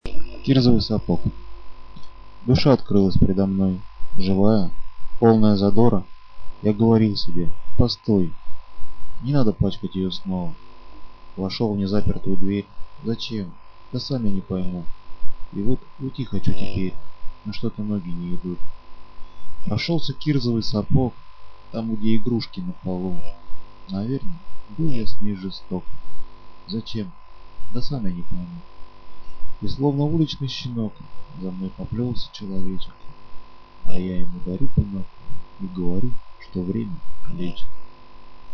Скачать MP3 файл с этим стихом в исполнении автора (500 кб)